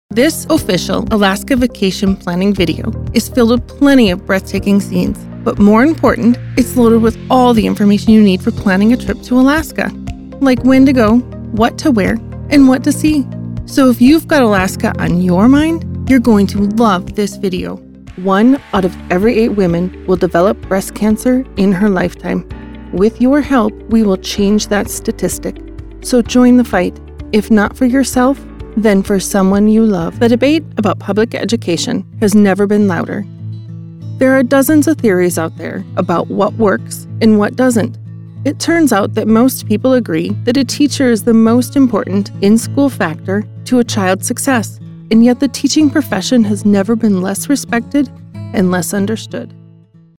Professional Female Voice Over Talent
Your voice is clear, and you add characterizations and dramatic emphasis to the narration.